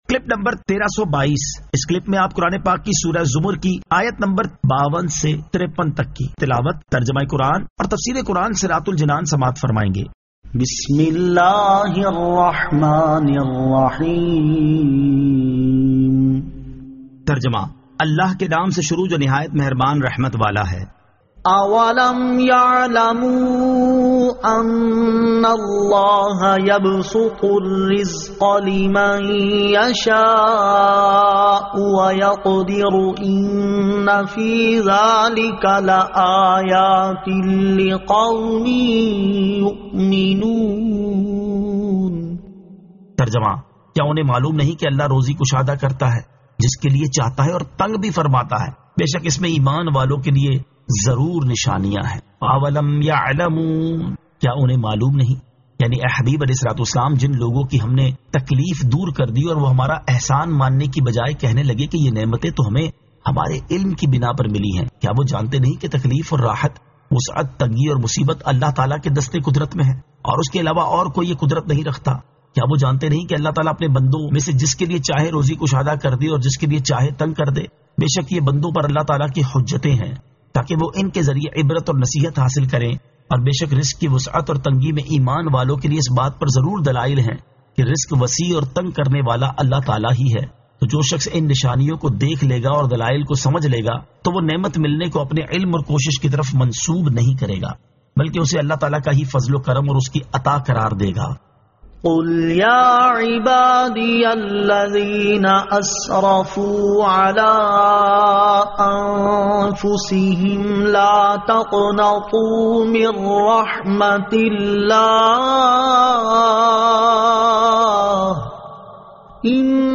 Surah Az-Zamar 52 To 53 Tilawat , Tarjama , Tafseer